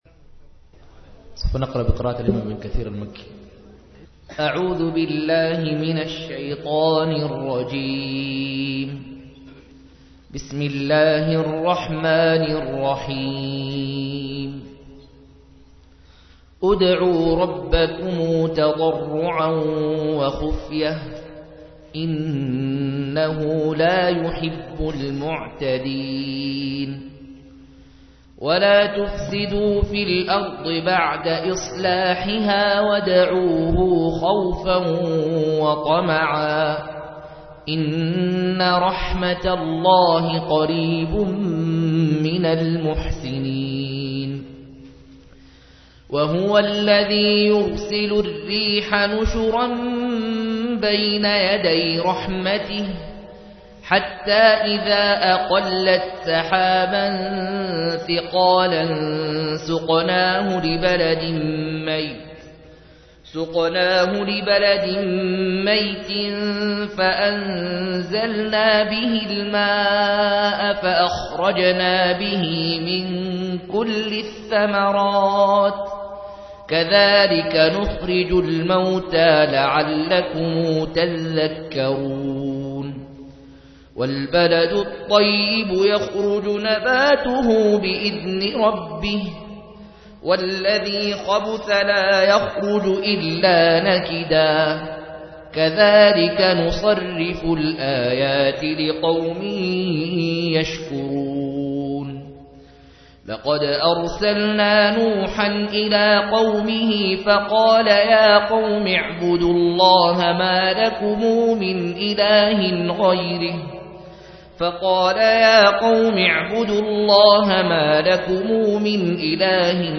150- عمدة التفسير عن الحافظ ابن كثير رحمه الله للعلامة أحمد شاكر رحمه الله – قراءة وتعليق –